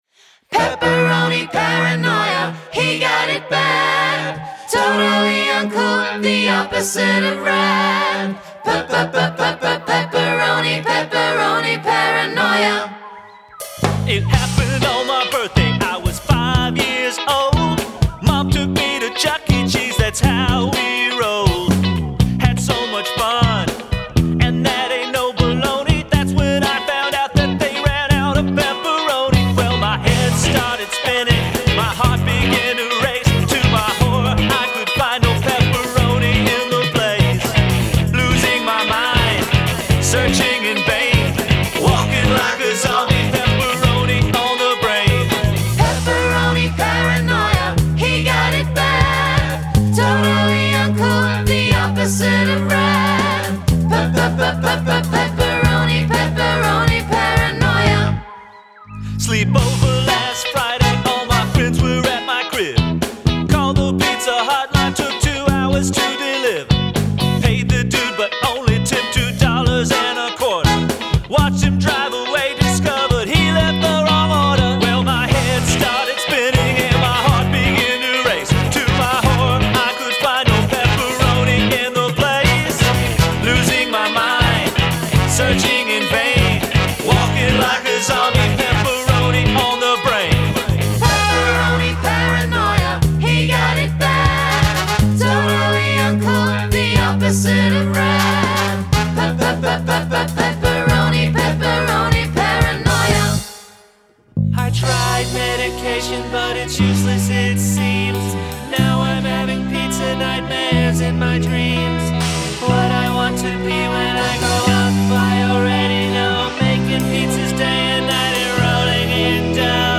Genre: Tween Rock/Ska